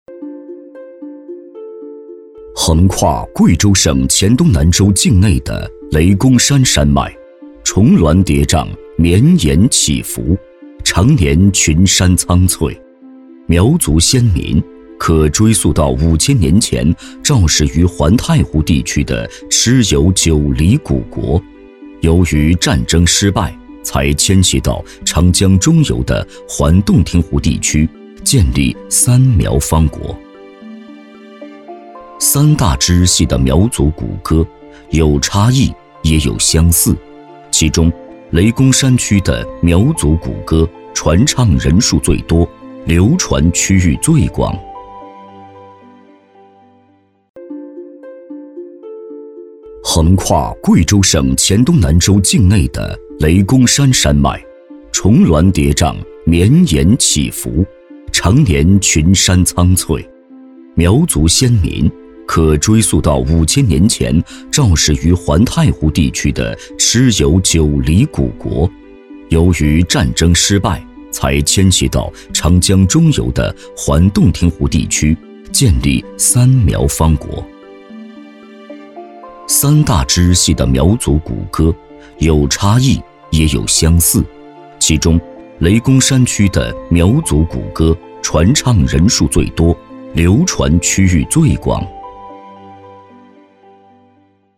国语青年大气浑厚磁性 、科技感 、积极向上 、男广告 、300元/条男S353 国语 男声 广告-宝捷讯项目-房地产广告-沉稳大气 大气浑厚磁性|科技感|积极向上